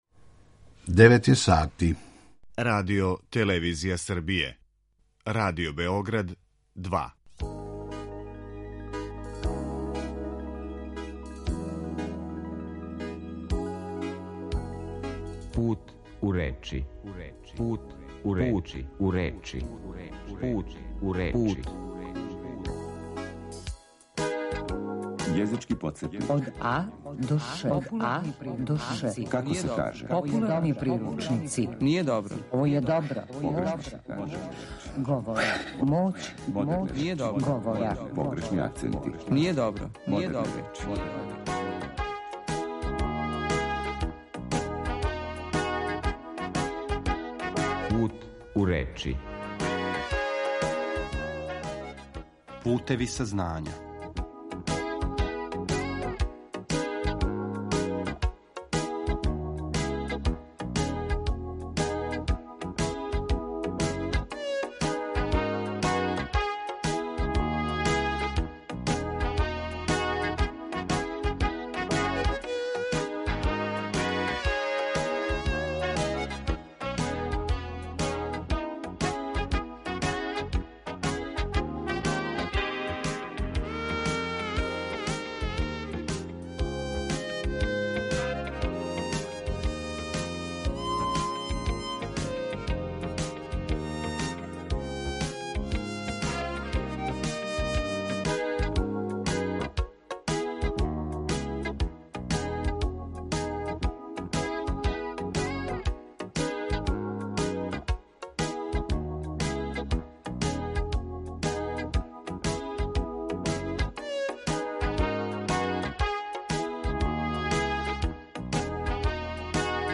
Емисија о лингвистици, нашем књижевном језику у теорији и пракси, свакодневној вербалној комуникацији и говору на медијима.